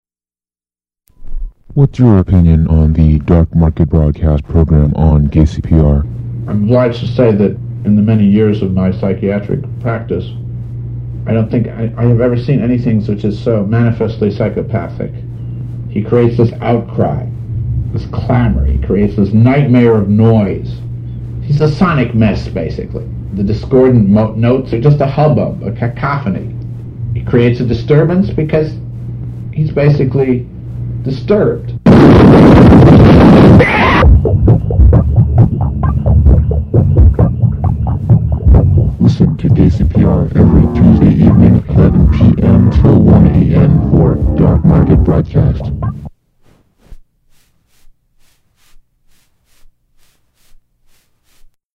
Form of original Audiocassette